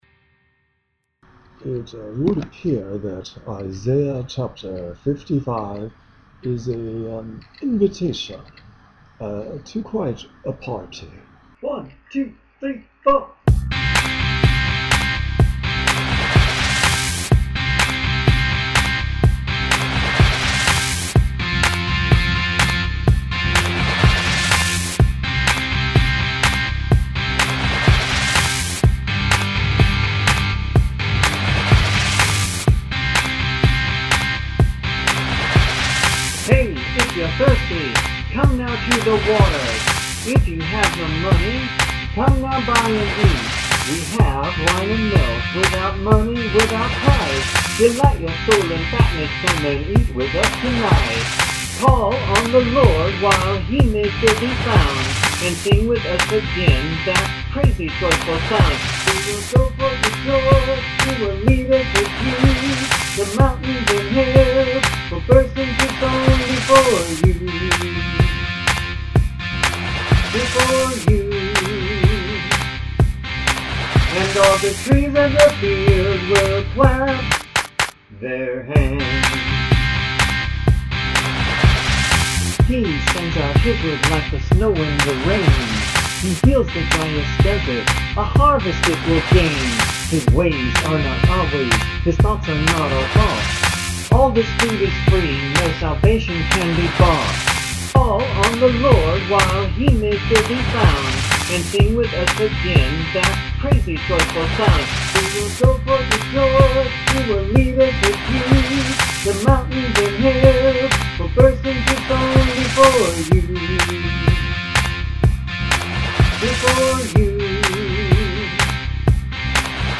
extreme low tones and bass ... and these will not play